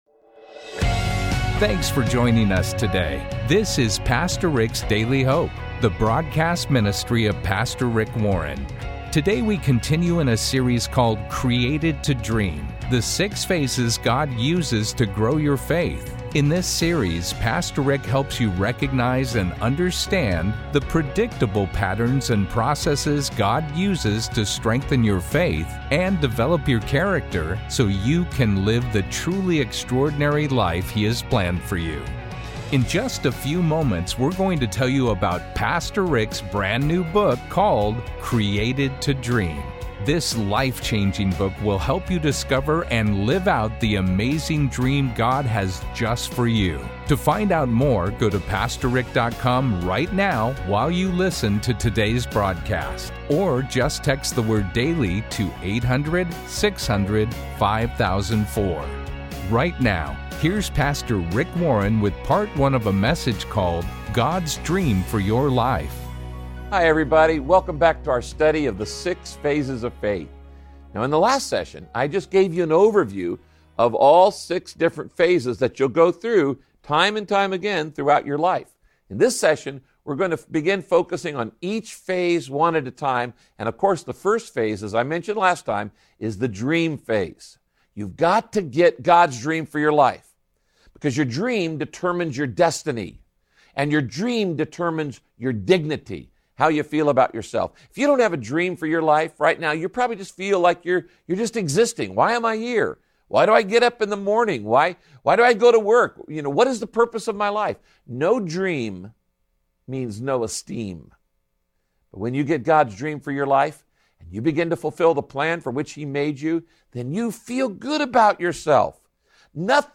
In this broadcast, Pastor Rick teaches how to follow God’s dream instead of settling for your culture's idea of success.